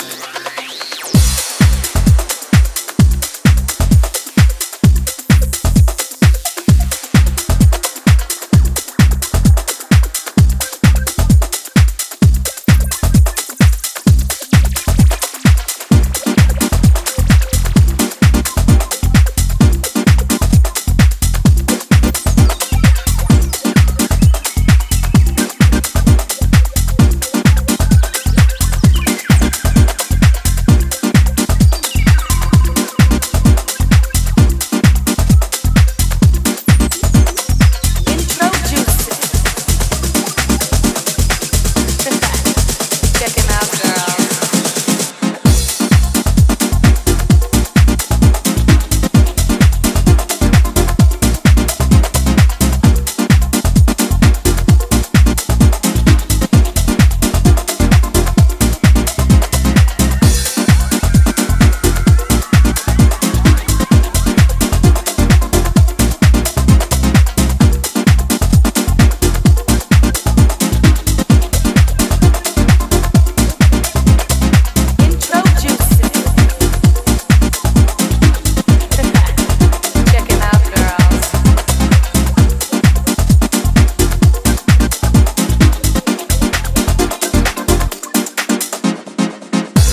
ピークタイムに効力を発揮するブレイクを仕掛けながらも、全体的にはディープ・ハウス・マナーを守った